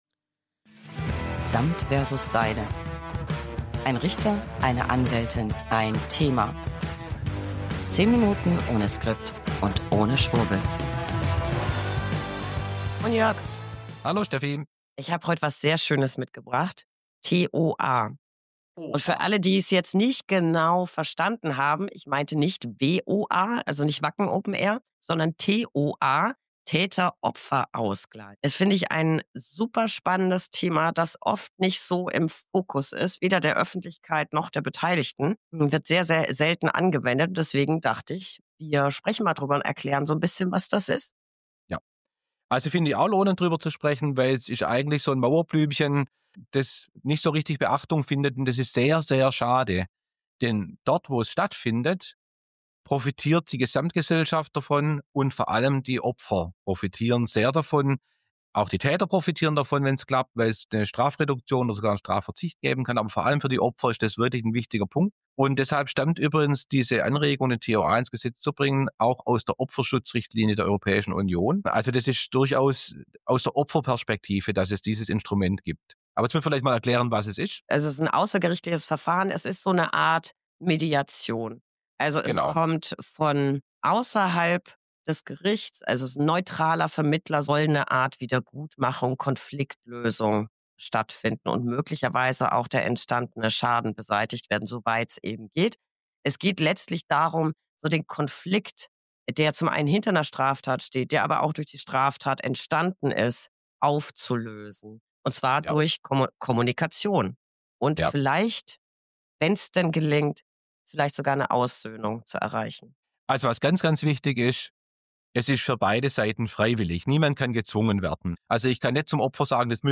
Beschreibung vor 9 Monaten 1 Anwältin + 1 Richter + 1 Thema. 10 Minuten ohne Skript und ohne Schwurbel.